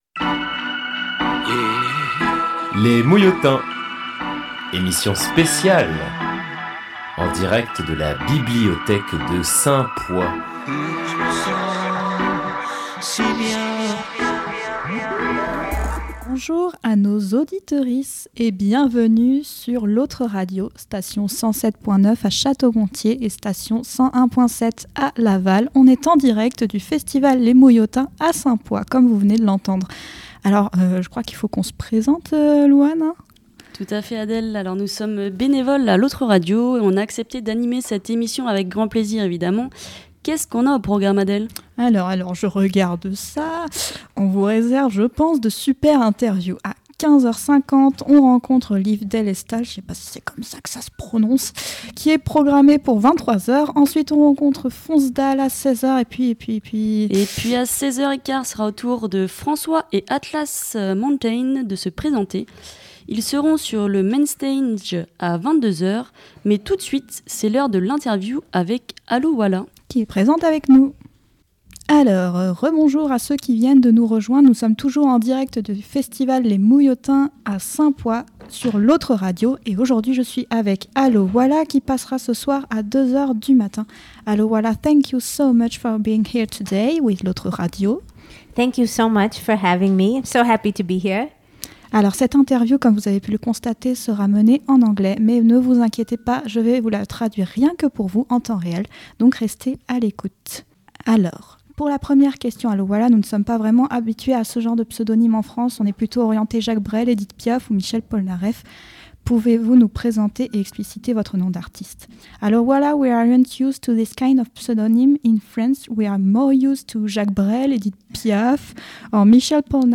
L’Autre Radio installe son studio aux Mouillotins : une heure de radio en direct de Saint-Poix pour un coup de projecteur sur ce festival militant.